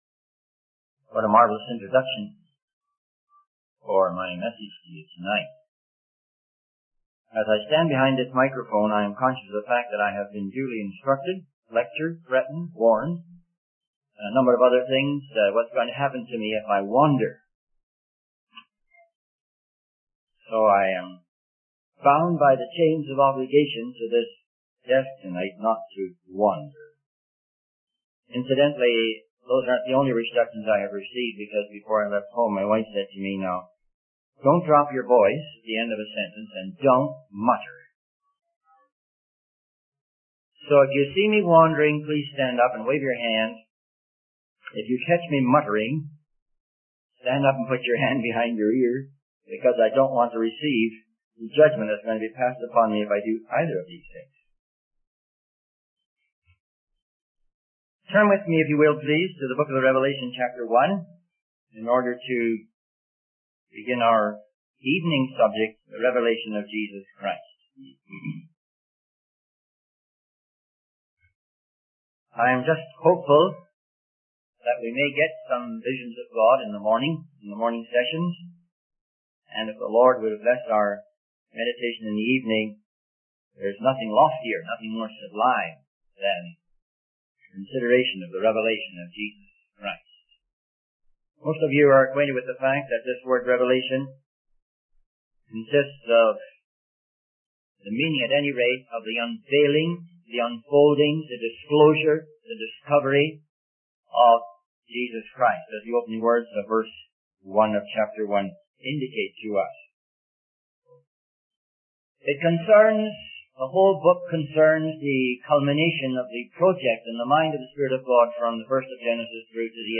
In this sermon, the speaker begins by acknowledging the instructions and restrictions he has received before delivering his message.